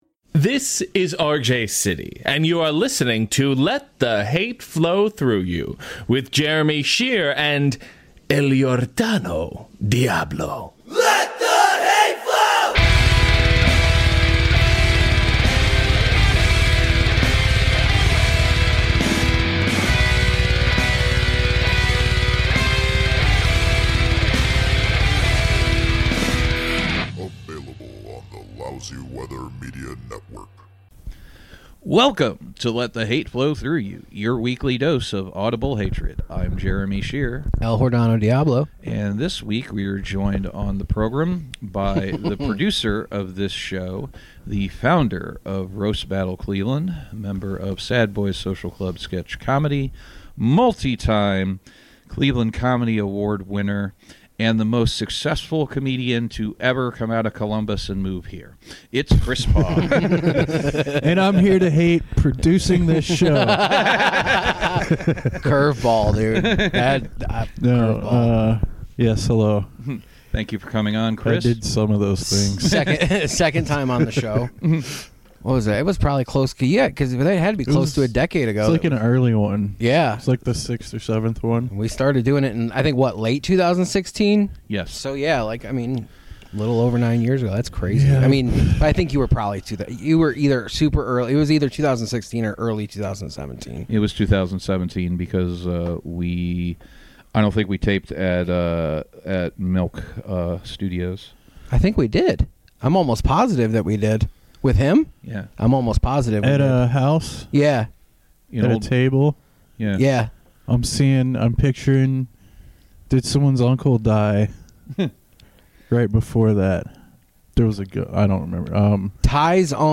Two caked up dudes interview a Sad Boy about his seasonal depression, yet hilarity ensues.